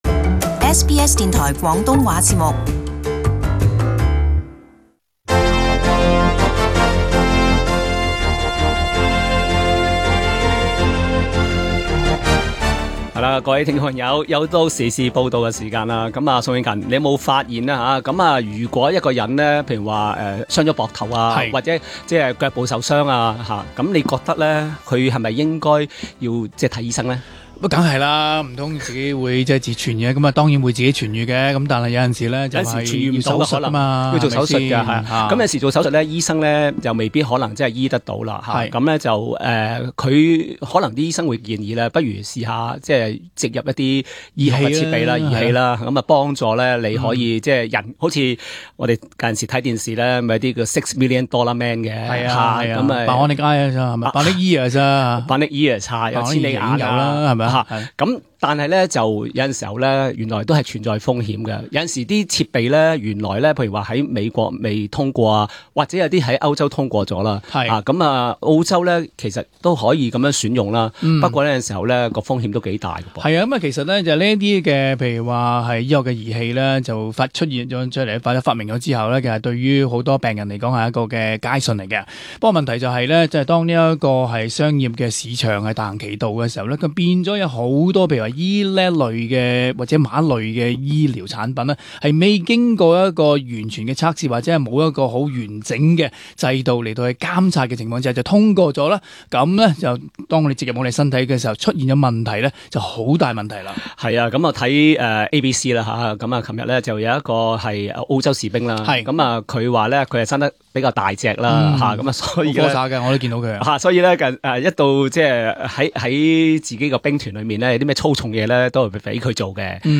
【時事報導】 醫療裝置故障 十年導致 8 萬多人死亡